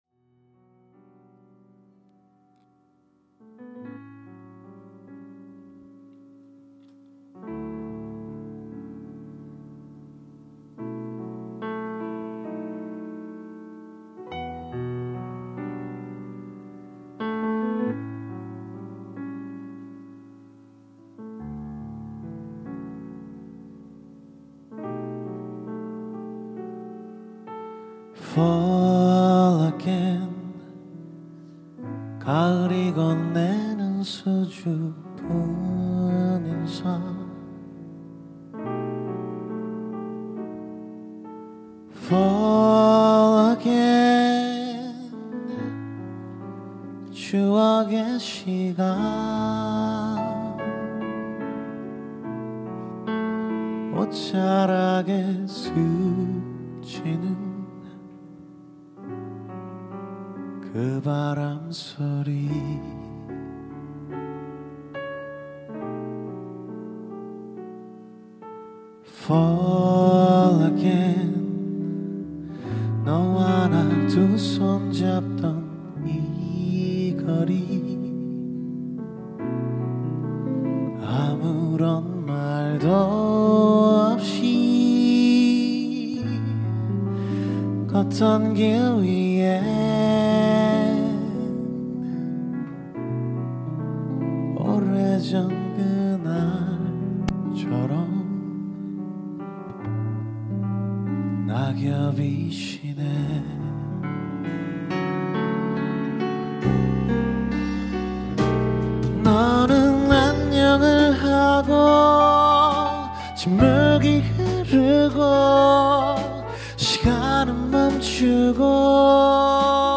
음악 – 지난 프리즘 합주 음원.
악기 발란스가 좋지는 않지만
원곡과는 다른 피아노 버젼의